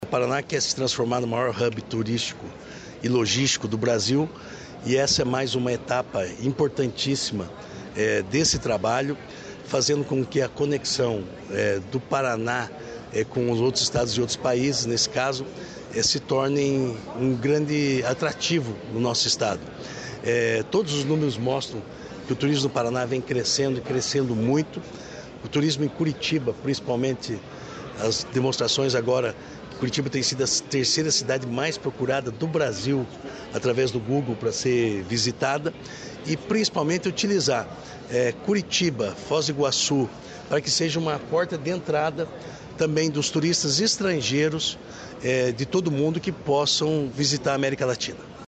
Sonora do secretário de Turismo, Márcio Nunes, sobre o voo de Curitiba para Montevidéu